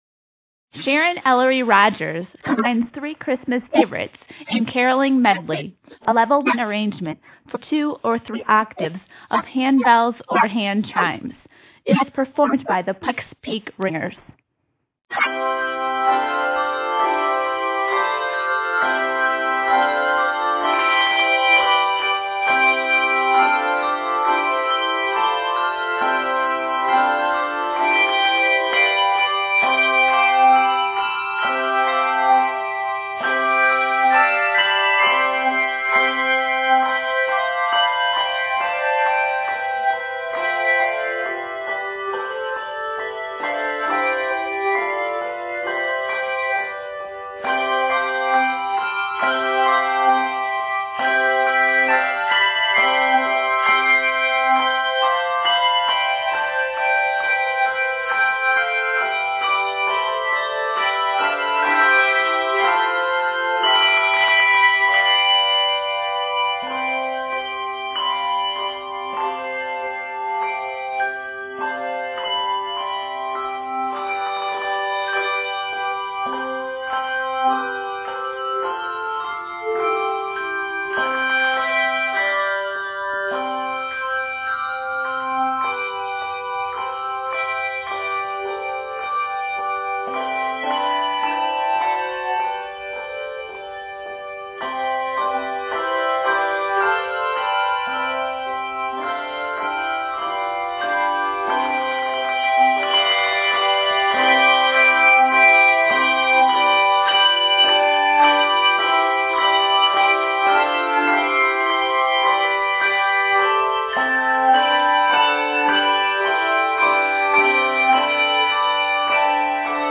Bells or chimes